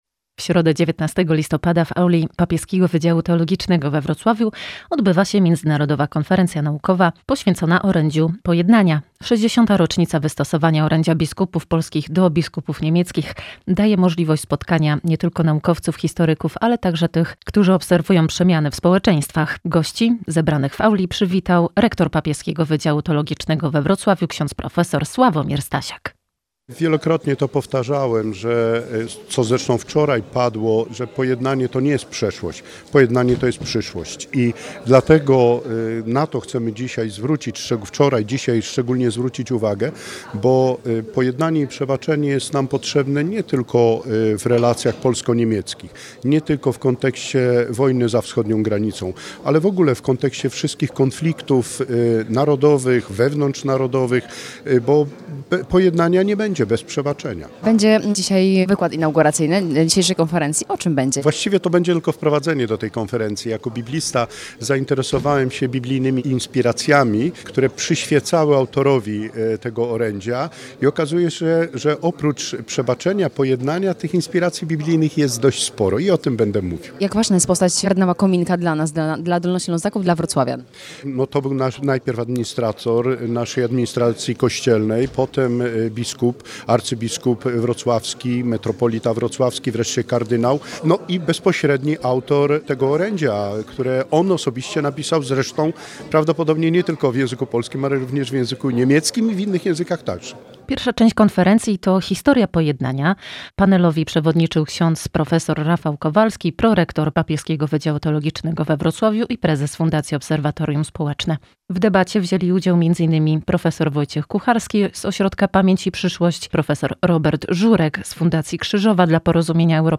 W auli Papieskiego Wydziału Teologicznego trwa konferencja naukowa związana z 60. rocznicą wystosowania listu Biskupów Polskich do Biskupów Niemieckich w 1965 roku, który otworzył drogę ku budowaniu dialogu między narodami w powojennej Europie.